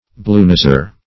Bluenoser \Blue"nos`er\)